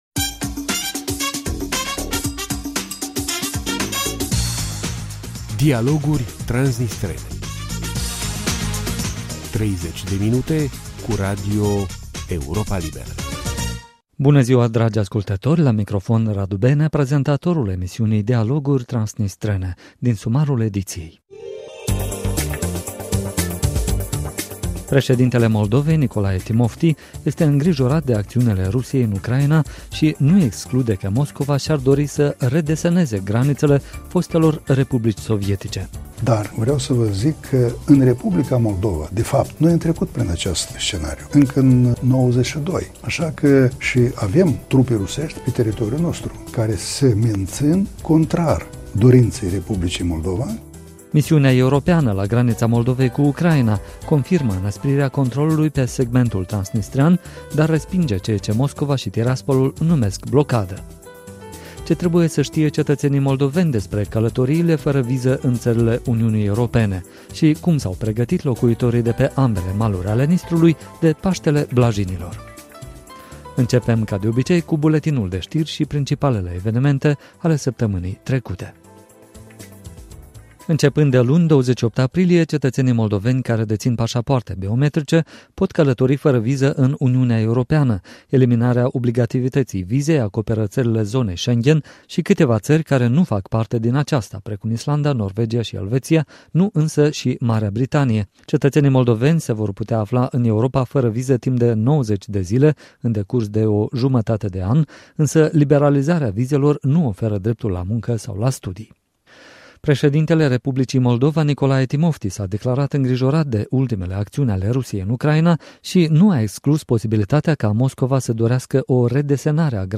Interviu cu preşedintele R. Moldova Nicolae Timofti. Misiunea EUBAM respinge ceea ce Moscova şi Tiraspolul numesc blocadă. Ce trebuie să ştie cetăţenii moldoveni despre călătoriile fără viză în ţările Uniunii Europene?